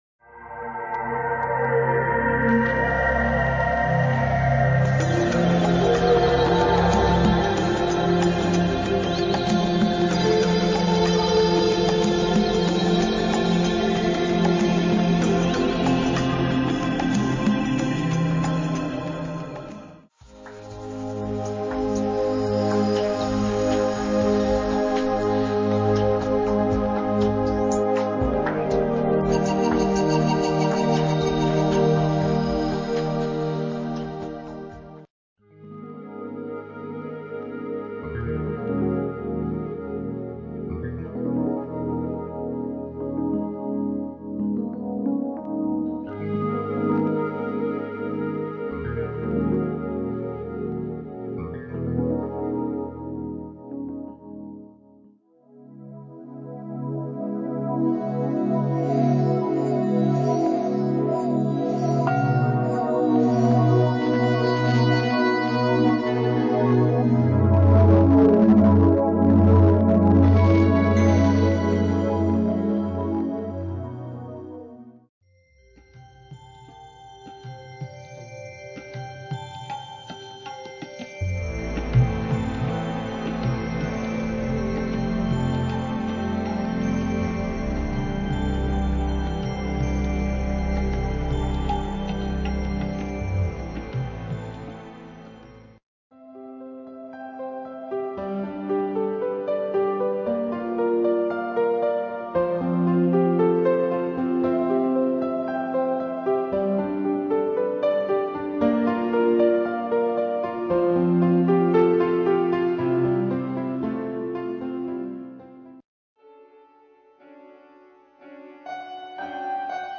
Relaxation